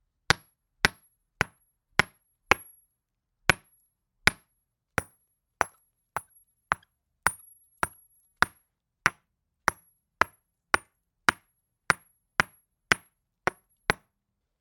锤击凿岩石
描述：锤击凿岩石。 M3gt;马兰士PMD661。
标签： 岩石凿 石锤 破岩 石破
声道单声道